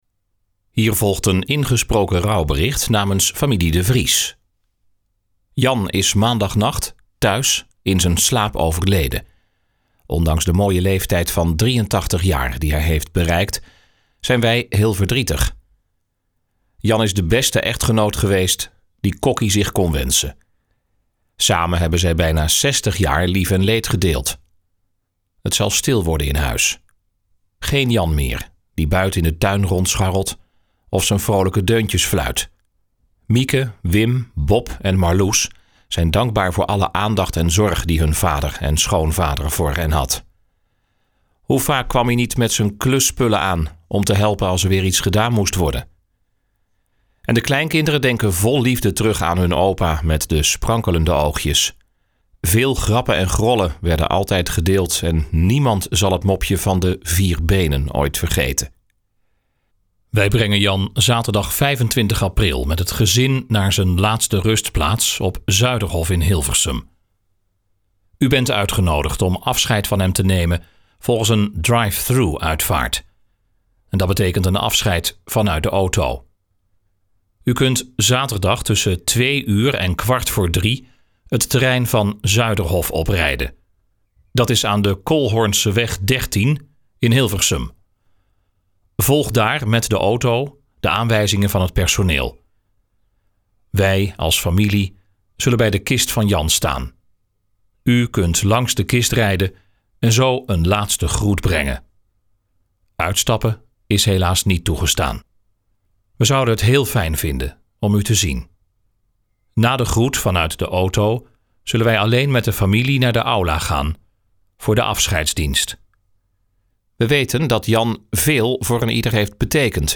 Ingesproken rouwbericht
Gesproken-rouwbericht.mp3